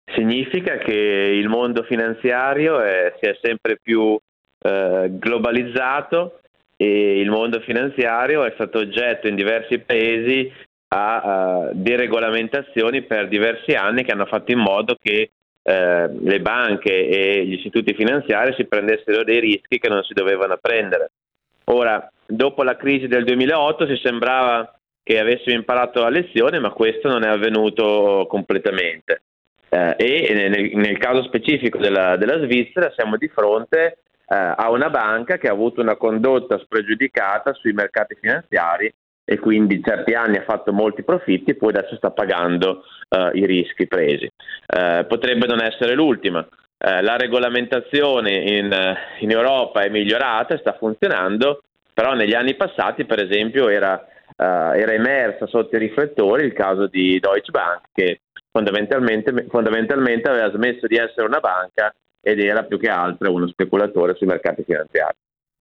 L’economista